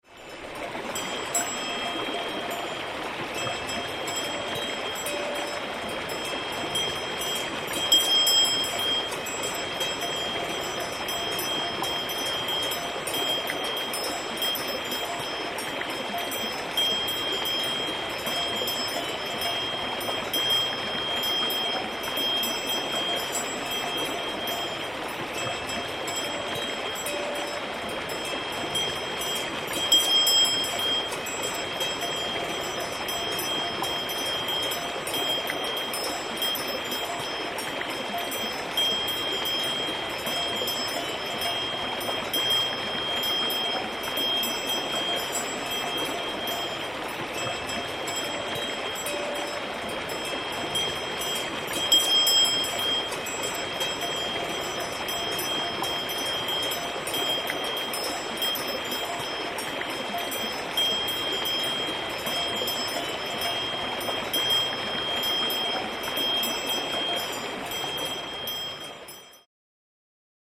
Horses at a mountain stream
An idyllic scene from Lago Antorno in the Italian Dolomites, as a pack of horses with cowbells attached to their necks feeds on lush grass next to a mountain stream. In this recording you can hear the horses, the bells and the water rushing by in a binaural recording taken close by.